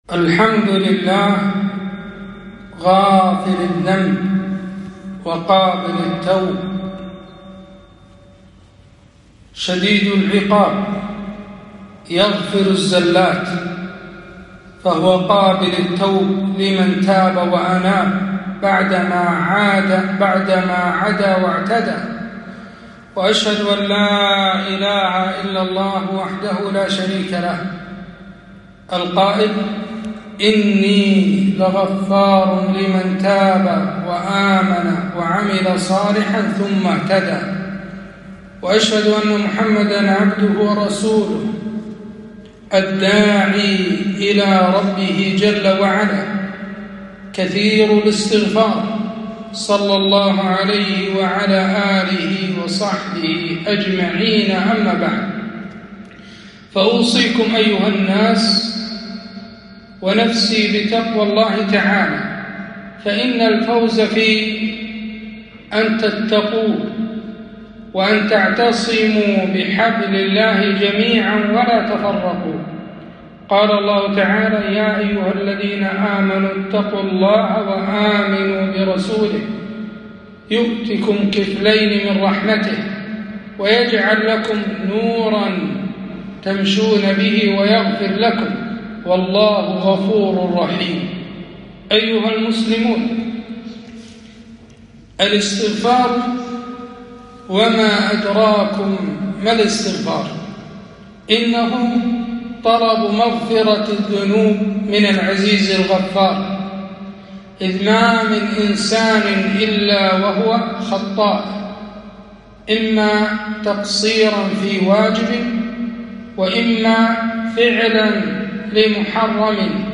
خطبة - استغفر الله وأتوب إليه